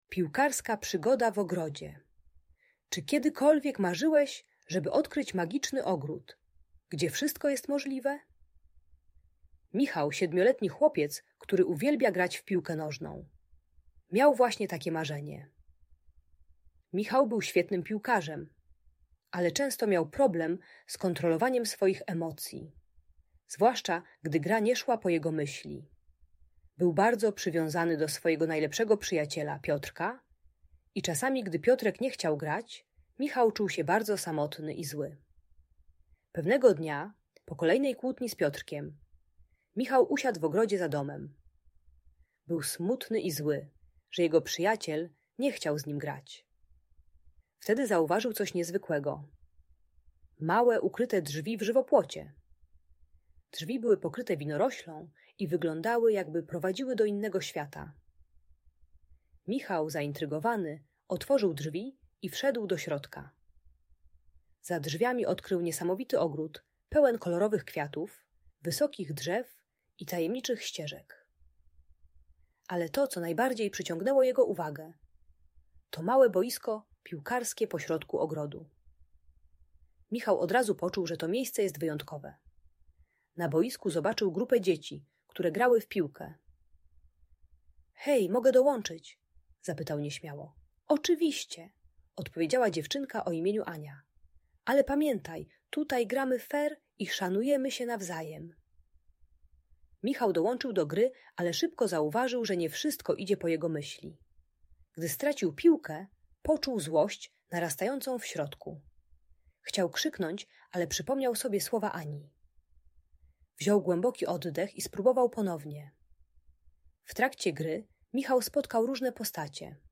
Piłkarska przygoda w ogrodzie - Bunt i wybuchy złości | Audiobajka